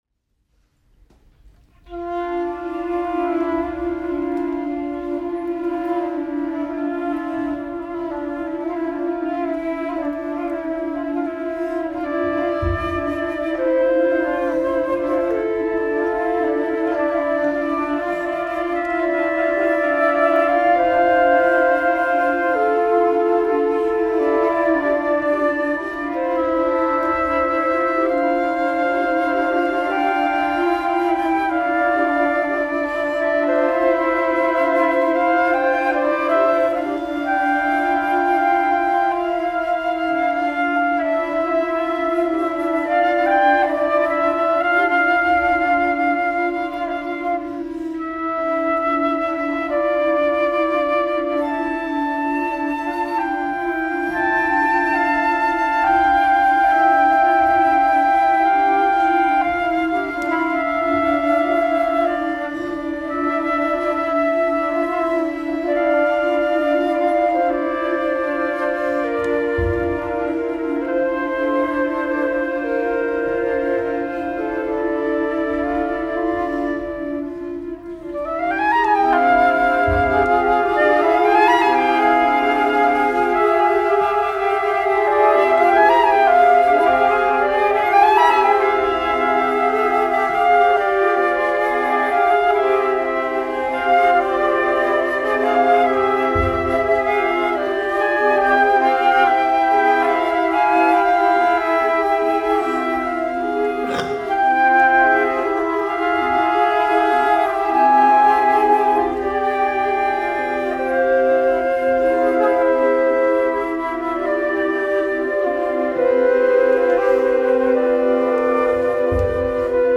for flute choir